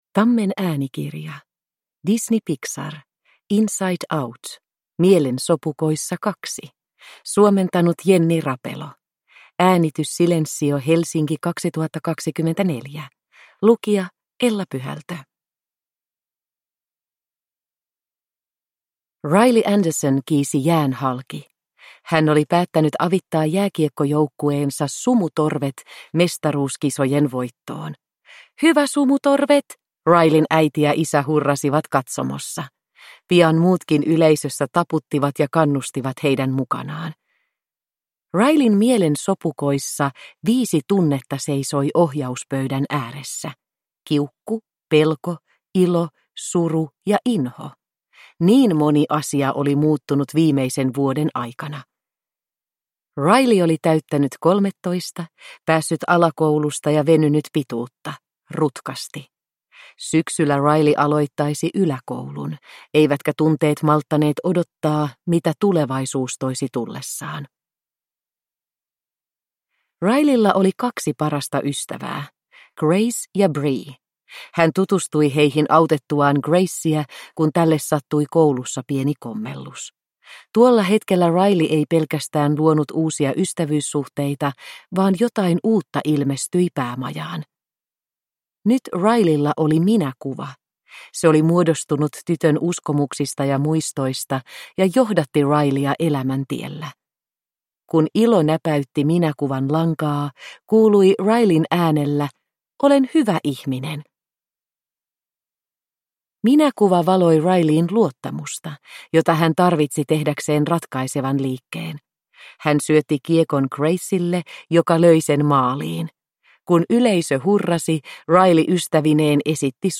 Disney Pixar. Inside Out 2. Satuklassikot – Ljudbok